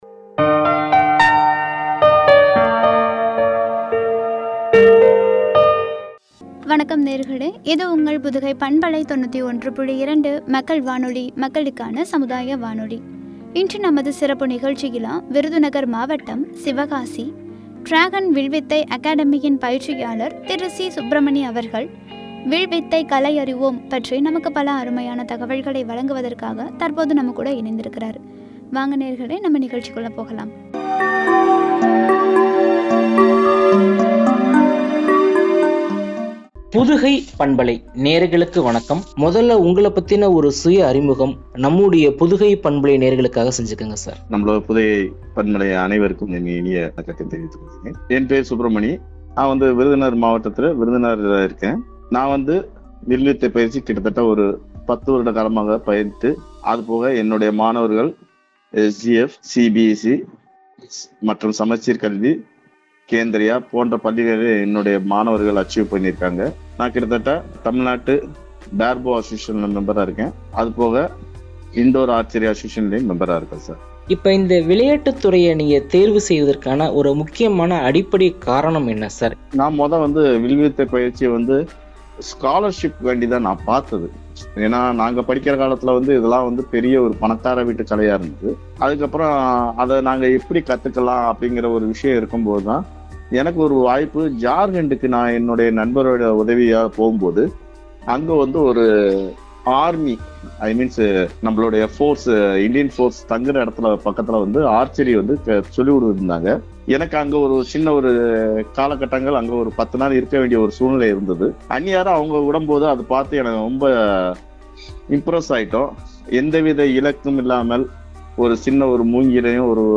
“வியத்தகு கலை: வில்வித்தை”என்ற தலைப்பில் வழங்கிய உரையாடல்.